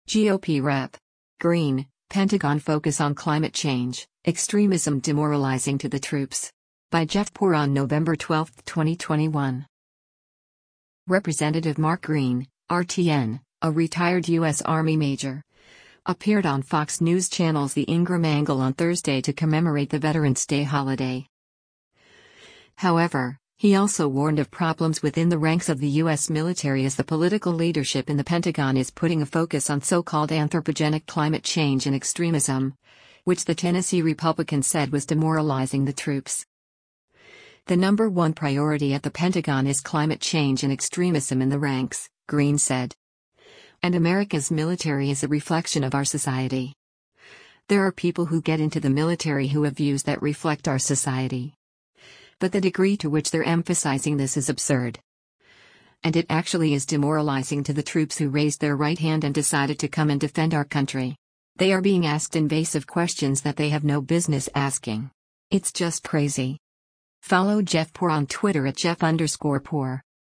Representative Mark Green (R-TN), a retired U.S. Army major, appeared on Fox News Channel’s “The Ingraham Angle” on Thursday to commemorate the Veterans Day holiday.